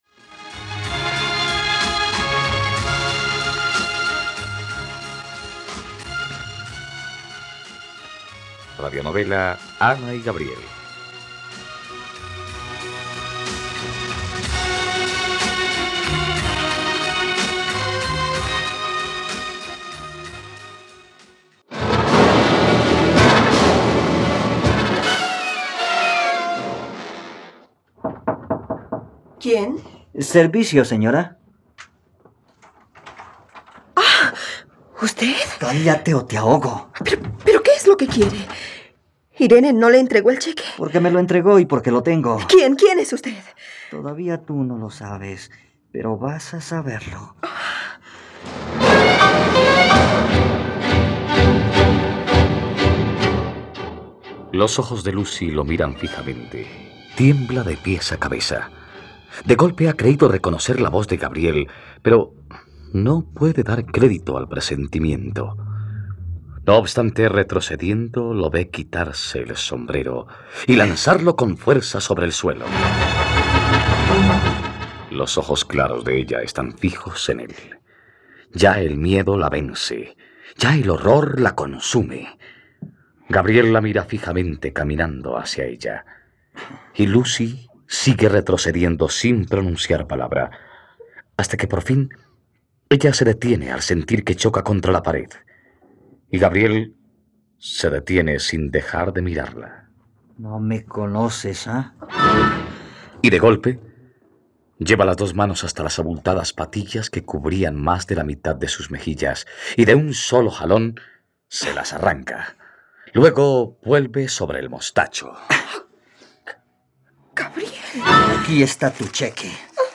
..Radionovela. Escucha ahora el capítulo 123 de la historia de amor de Ana y Gabriel en la plataforma de streaming de los colombianos: RTVCPlay.